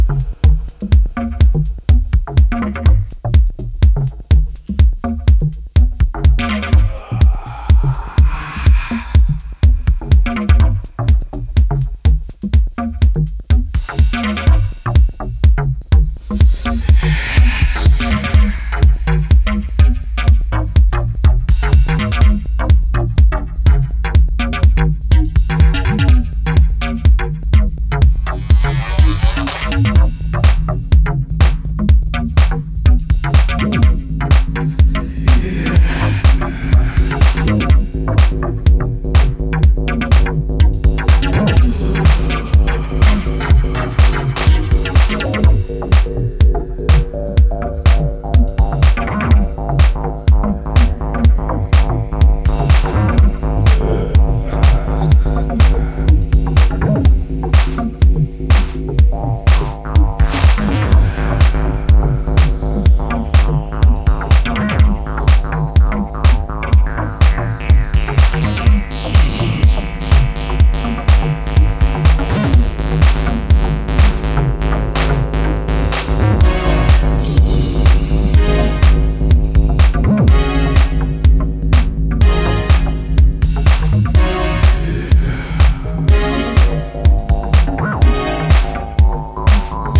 TECHNO/ELECTRO